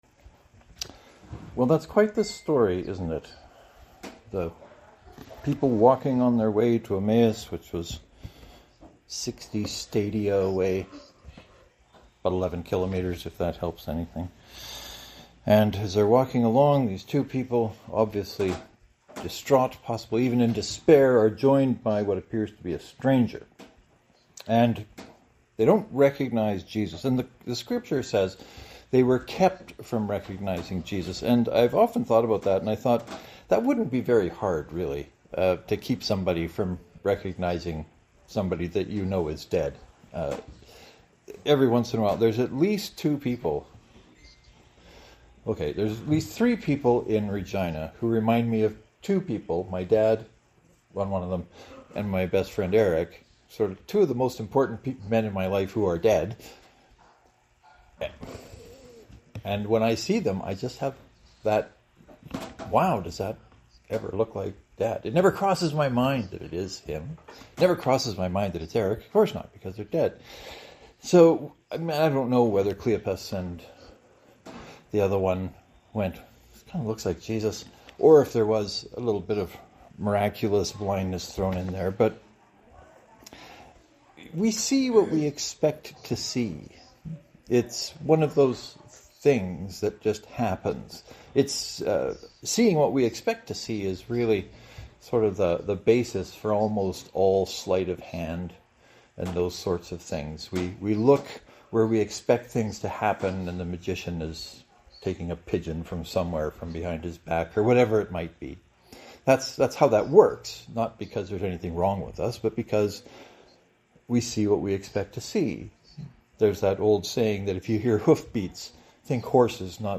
So why the sermon title about no one being blind? I wanted to think about what it means to see.